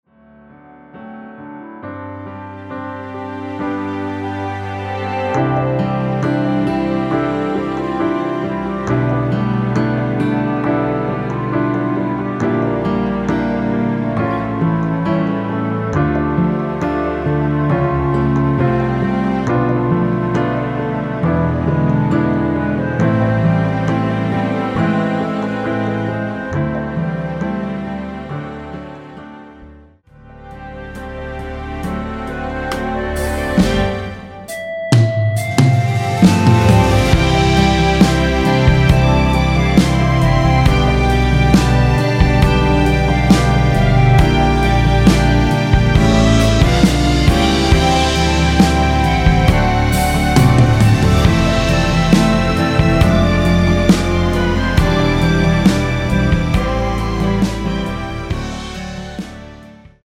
전주가 길어서 미리듣기는 중간 부분 30초씩 나눠서 올렸습니다.
원키에서(-4)내린 멜로디 포함된 MR입니다.
앞부분30초, 뒷부분30초씩 편집해서 올려 드리고 있습니다.